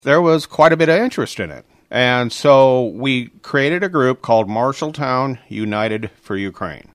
That was Mike Ladehoff, Marshalltown City Councilman, he mentions that they also have a Facebook page you can check out.